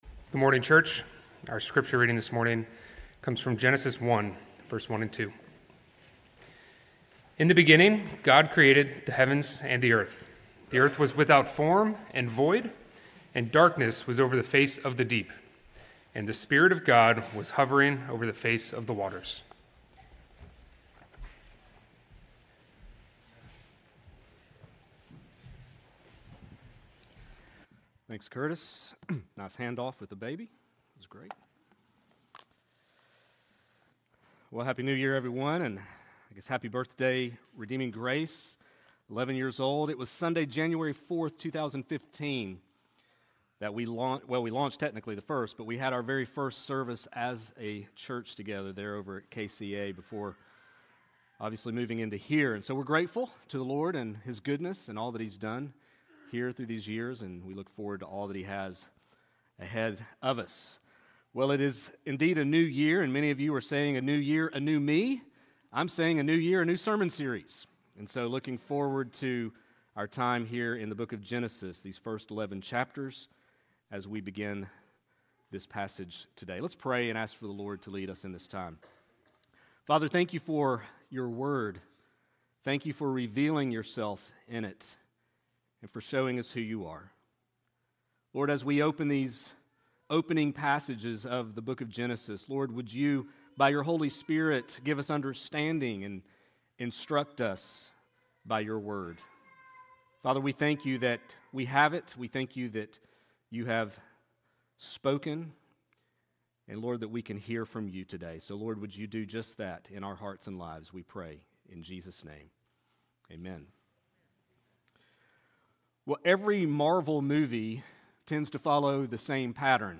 sermon1.4.26.mp3